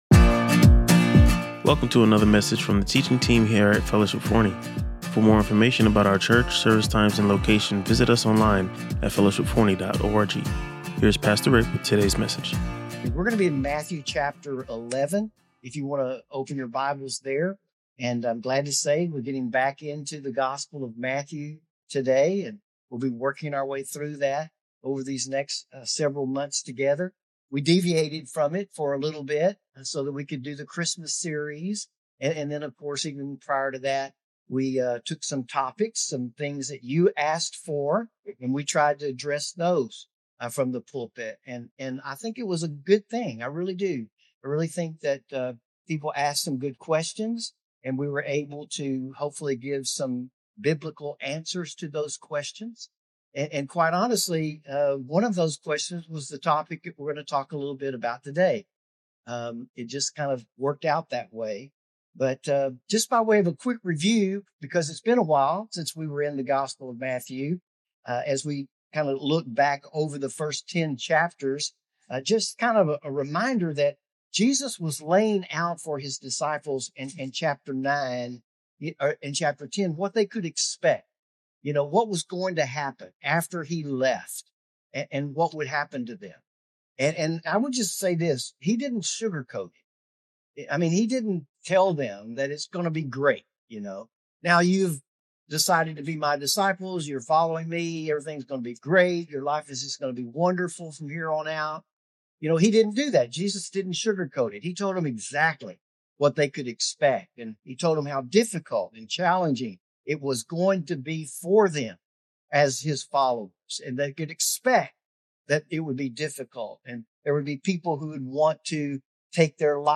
He examined how even the greatest prophet had moments of uncertainty while imprisoned. The sermon highlighted three key aspects: John’s questioning words to Jesus, Jesus’ reassuring response, and Christ’s powerful defense of John’s ministry.